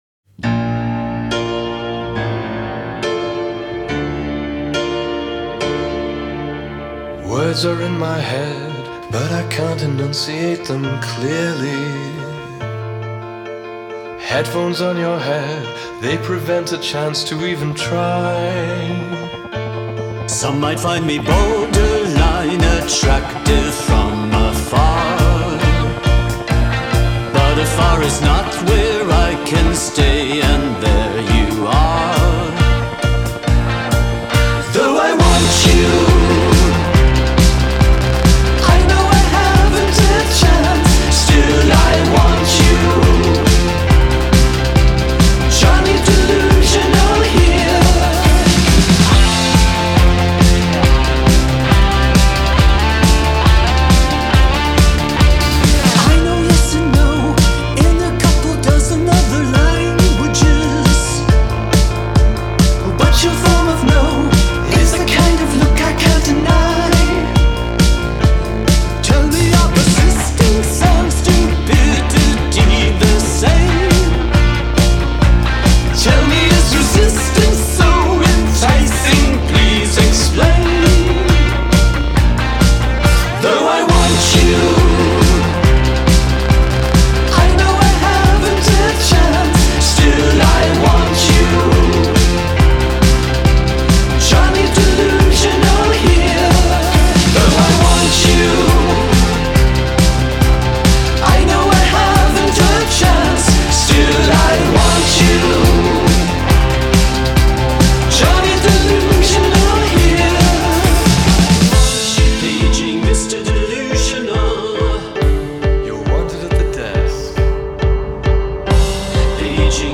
Genre: Indie Rock